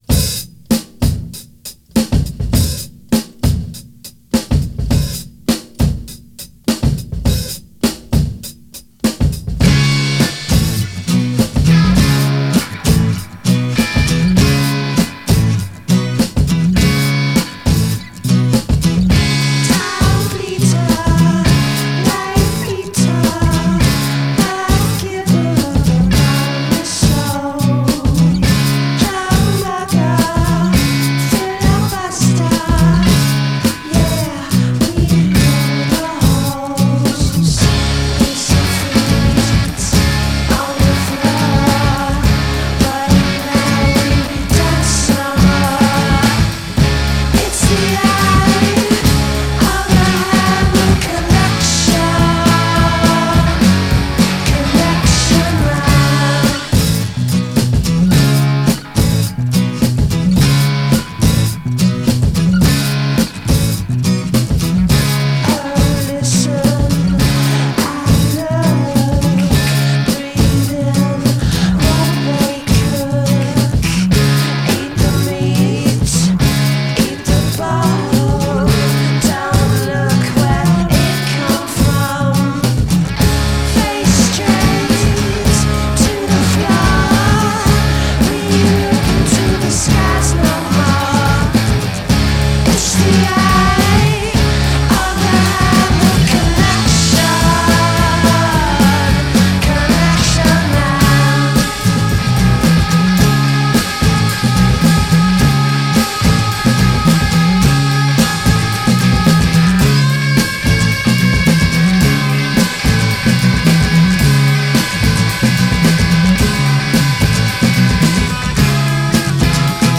Жанр: Garage.